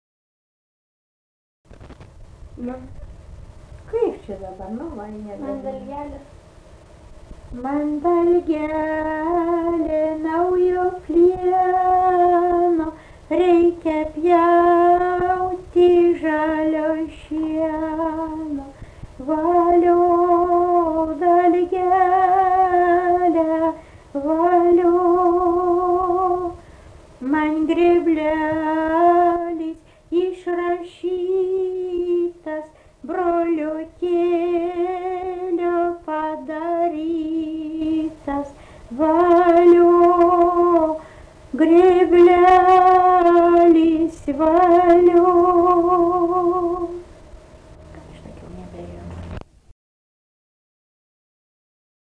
daina, kalendorinių apeigų ir darbo
Pušalotas
vokalinis